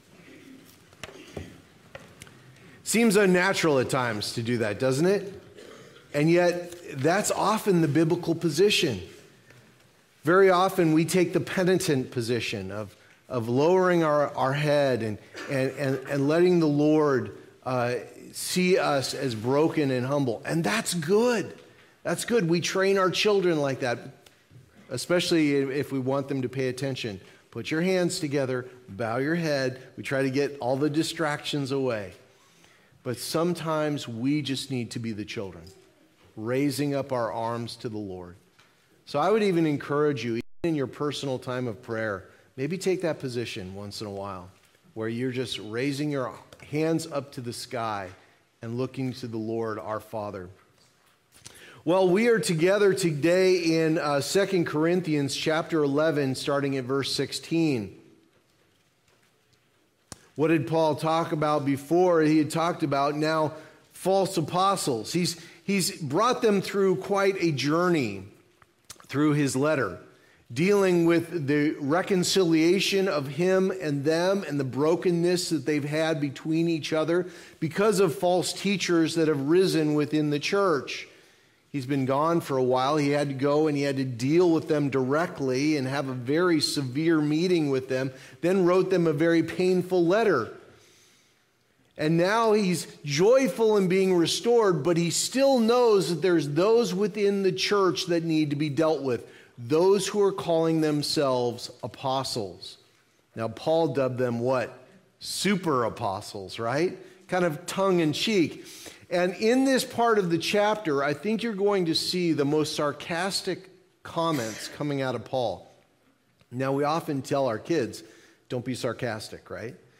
Sunday Morning Service Download Files Notes Previous Next